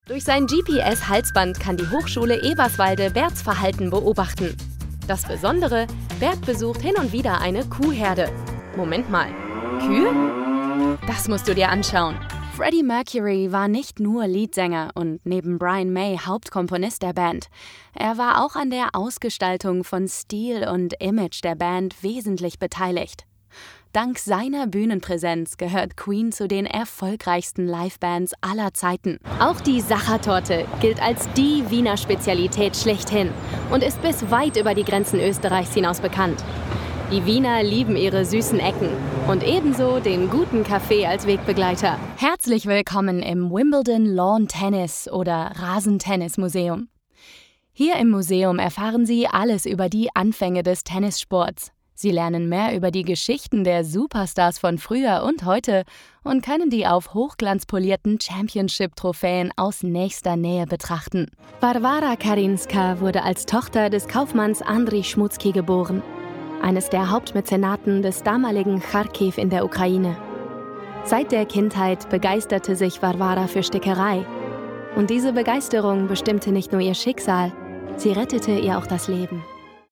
Narration
My voice is relatable, contemporary and youthful with a warm and textured sound.